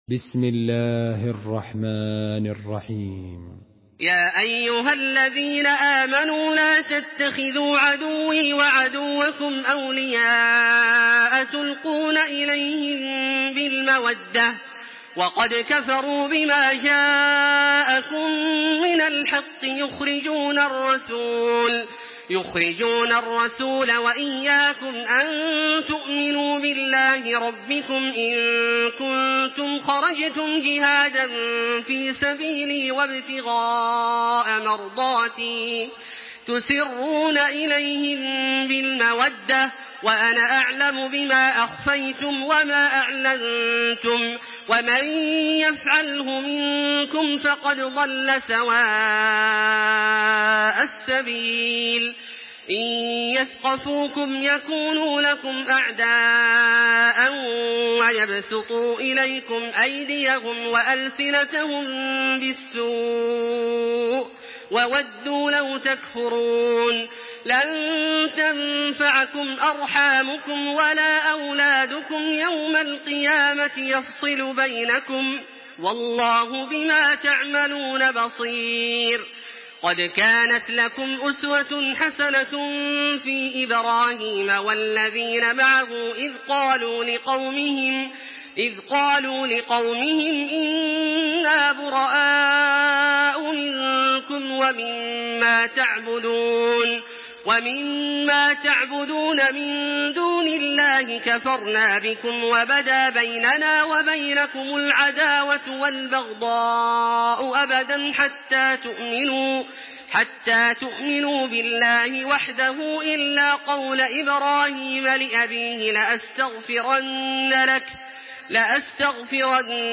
Download Surah Al-Mumtahinah by Makkah Taraweeh 1426
Murattal